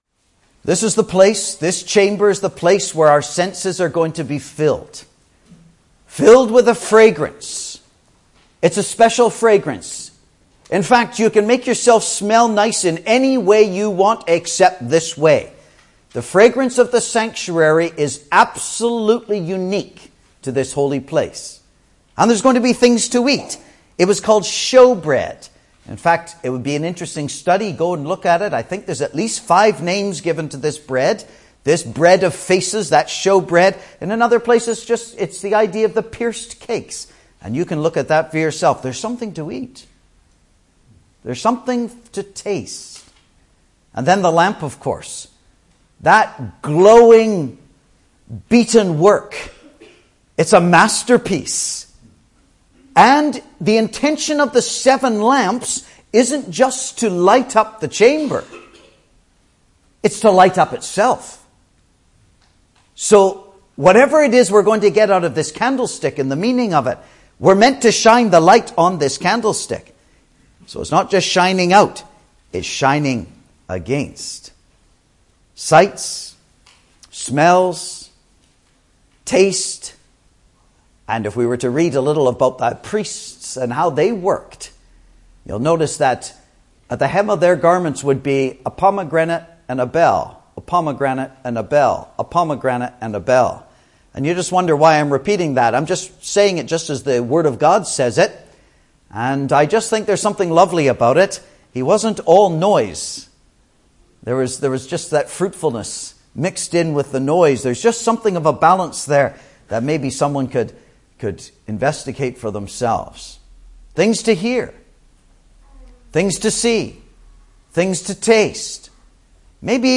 He explains why the door had 5 pillars, while the gate had only 4, even though they had the same square footage in surface area. Listen for his explanation as to why the lampstand had 22 buds, 22 flowers and 22 almonds (Message preached 10th Feb 2018)